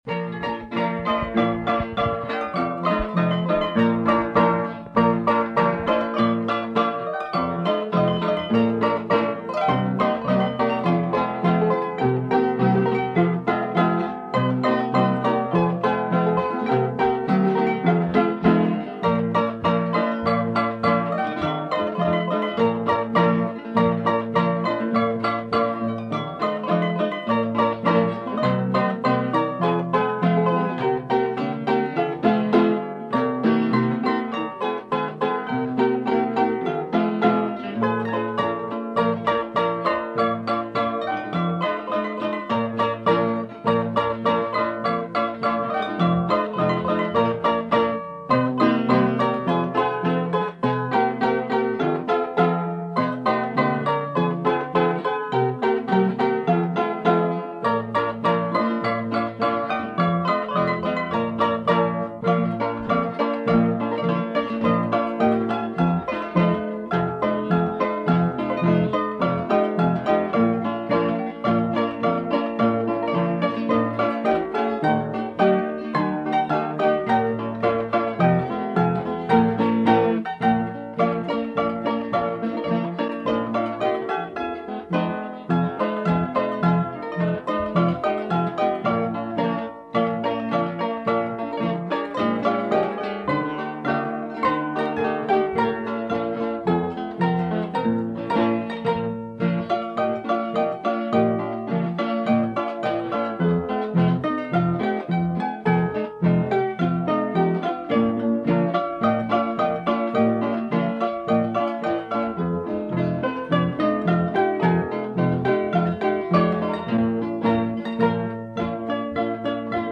Cordófonos